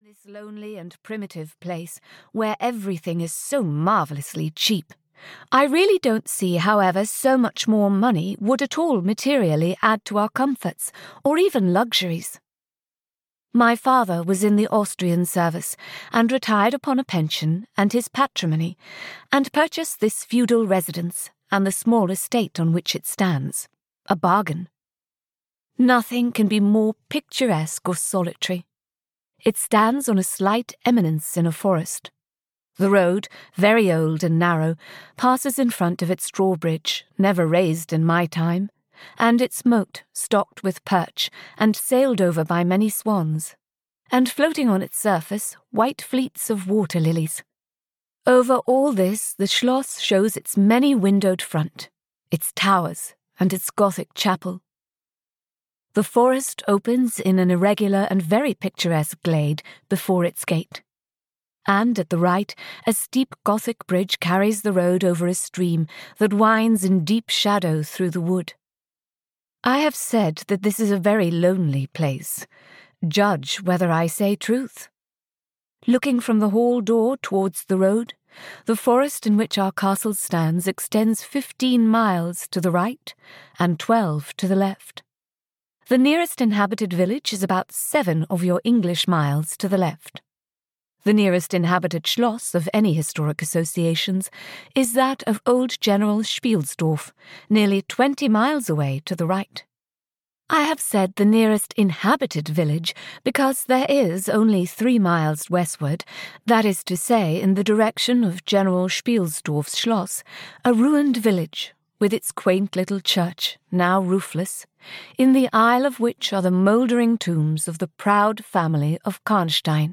Carmilla (EN) audiokniha
Ukázka z knihy